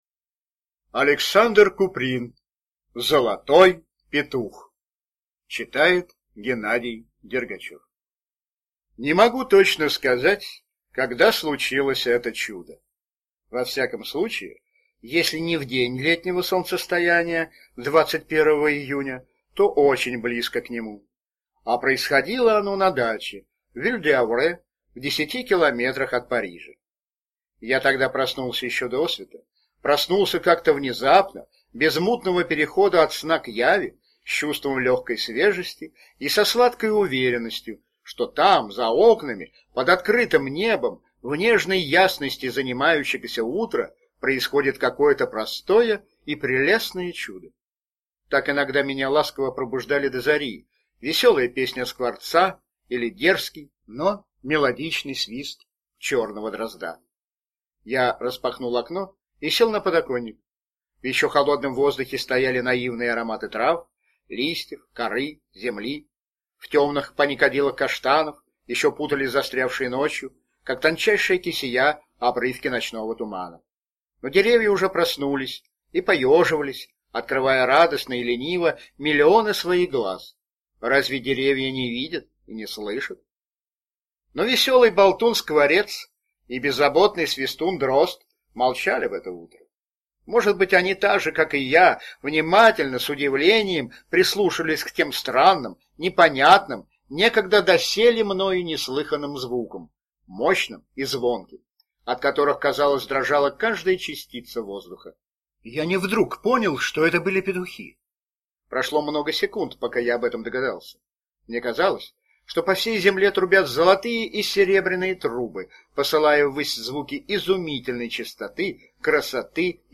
Золотой петух – Куприн А.И. (аудиоверсия)
Аудиокнига в разделах